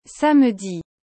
A pronúncia correta de samedi é algo como “sam-di”. O “e” do meio praticamente some, e o “d” final é suave.
• O “s” tem um som suave, nada de exagerar!